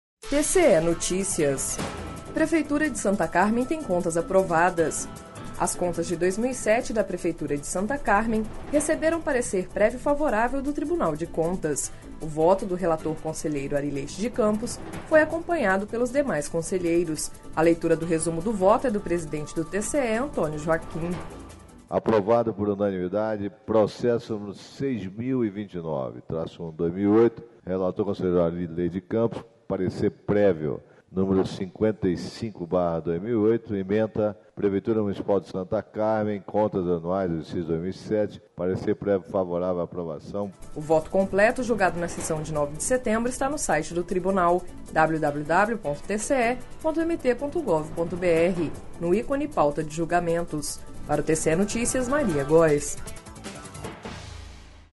A leitura do resumo do voto é do presidente do TCE, Antonio Joaquim.// Sonora: Antonio Joaquim – conselheiro presidente do TCE-MT